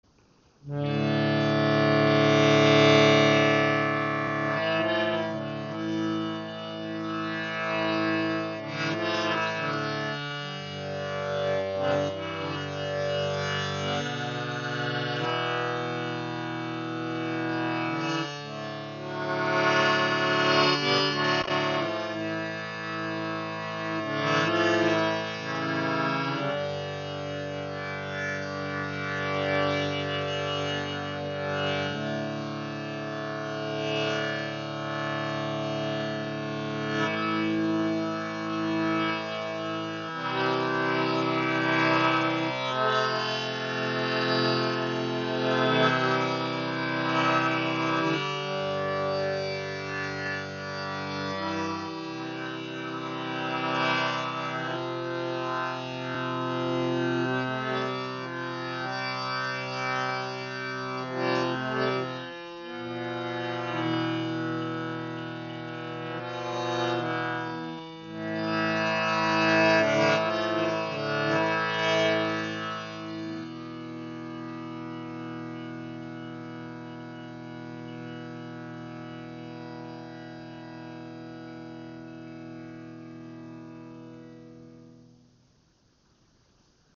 Klangbeispiel
Dieses sehr schön verarbeitete Harmonium aus edlem Zedernholz hat eine Klangbreite von 3½ Oktaven und eine sehr schöne Klangfarbe.
Jeder Ton ist mit je einer Zunge in mittlerem und tieferem Register ausgestattet, sodass sich ein voller, tragender Klang ergibt.